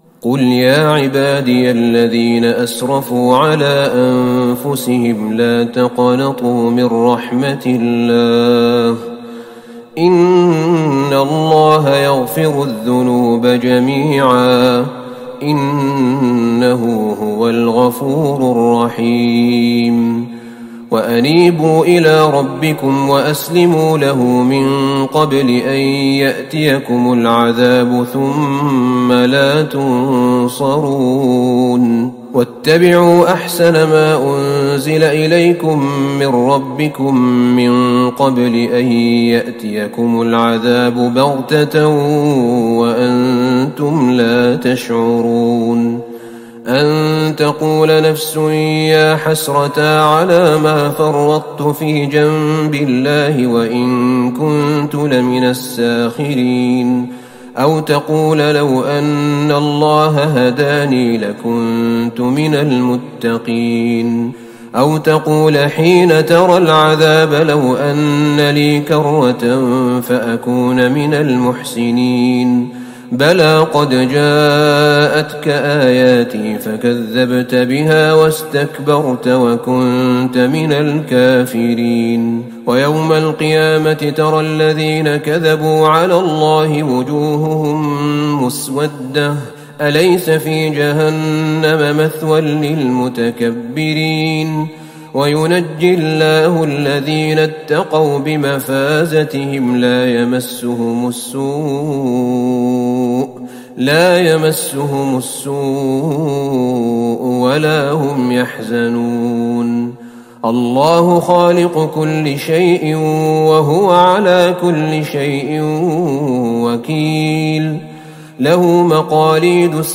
تراويح ٢٦ رمضان ١٤٤١هـ من سورة الزمر { ٥٣- النهاية } وغافر {١-٥٥} > تراويح الحرم النبوي عام 1441 🕌 > التراويح - تلاوات الحرمين